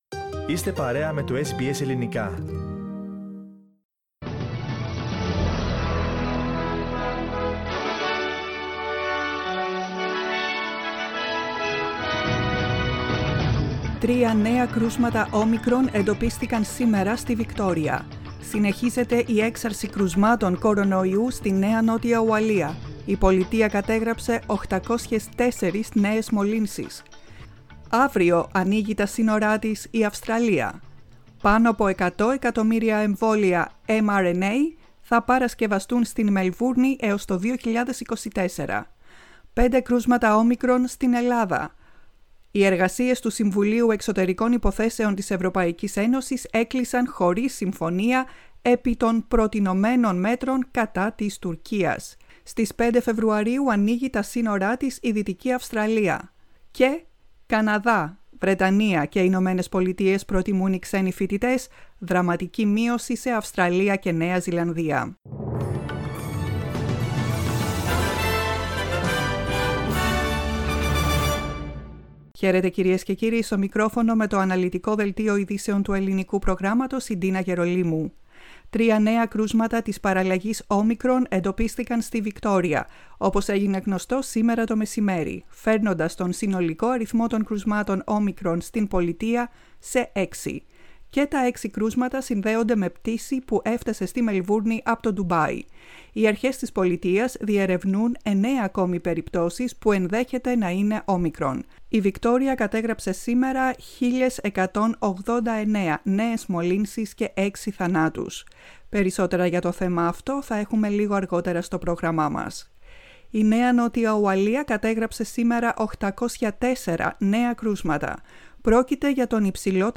News bulletin in Greek, 14.12.21